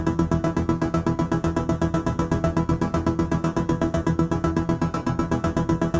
Index of /musicradar/dystopian-drone-samples/Tempo Loops/120bpm
DD_TempoDroneC_120-E.wav